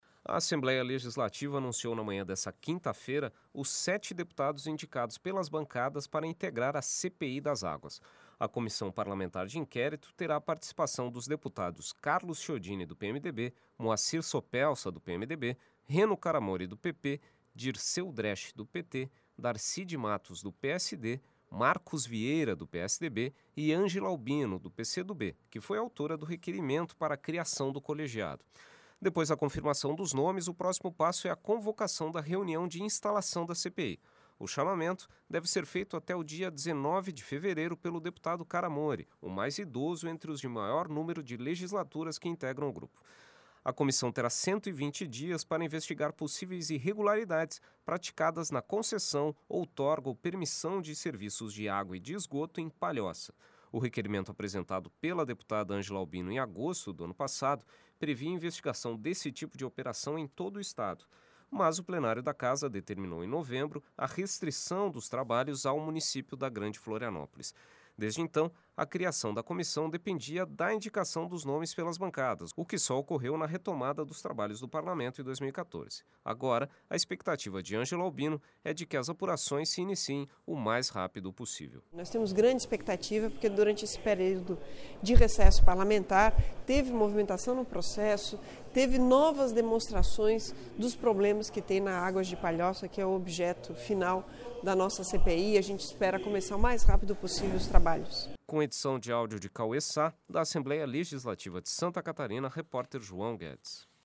Entrevista com: deputada Angela Albino (PCdoB).